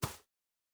Shoe Step Grass Hard D.wav